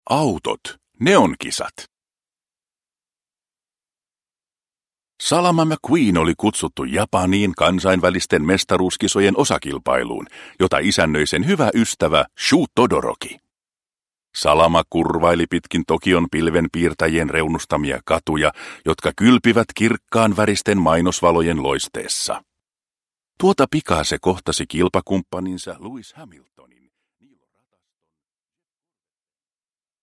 Disney Pixar Autot. Neonkisat – Ljudbok – Laddas ner